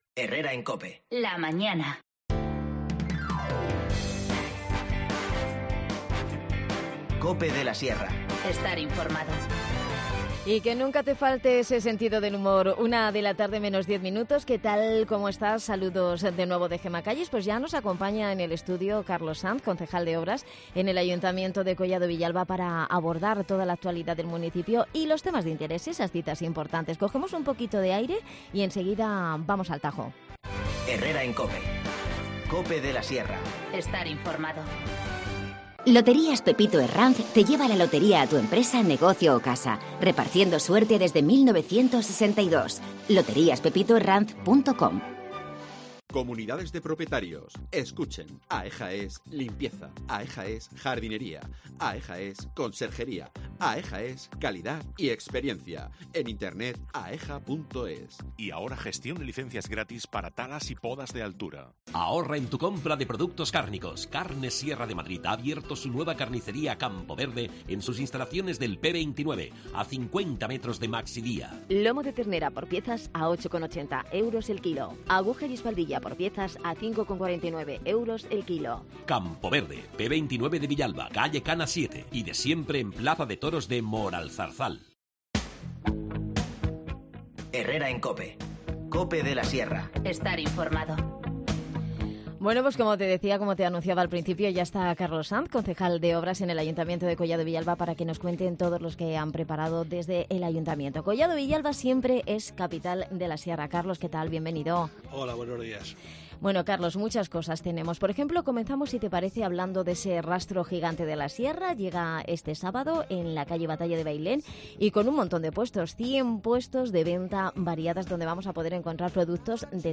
Carlos Sanz, concejal de Obras en Collado Villalba, nos visita para adelantarnos las citas y actividades que han preparado de cara a estos días. Muchas de ellas pensando en los niños jóvenes del municipio.